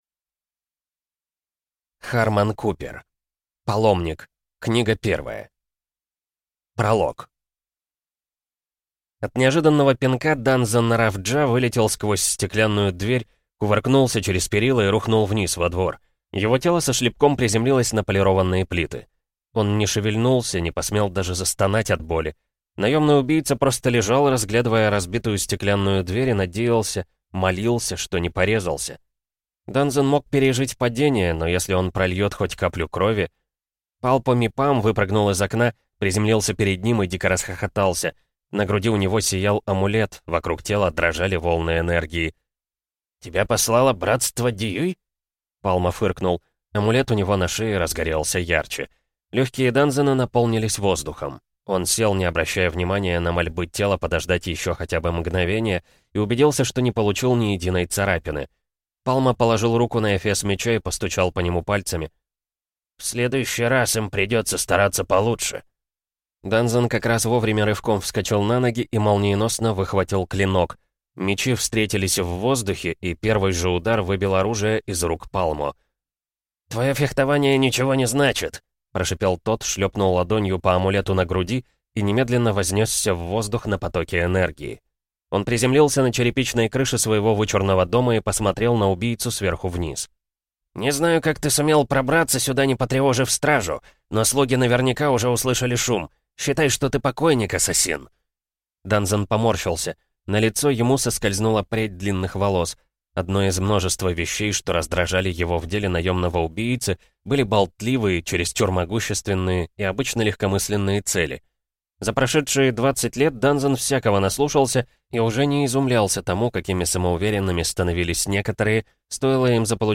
Аудиокнига Паломник. Книга первая | Библиотека аудиокниг